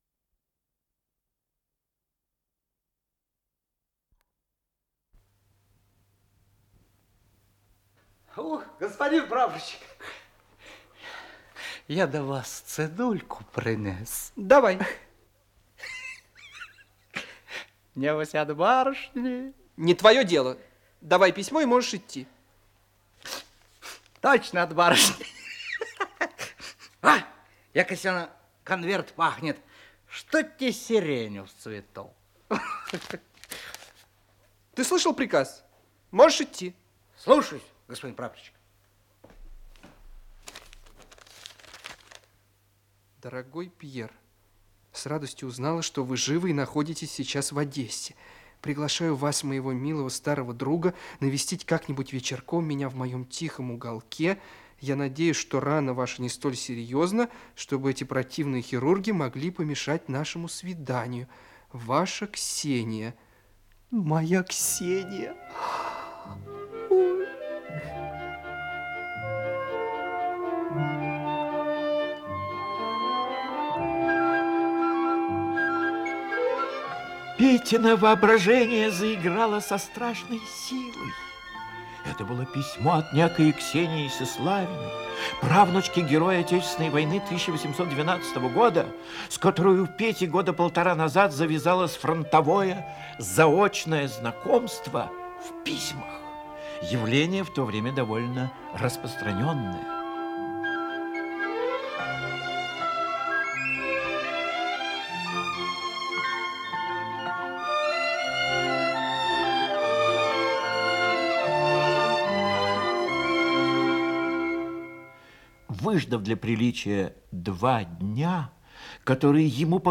Исполнитель: Артисты московских театров
Радиоспектакль по роману "Зимний ветер"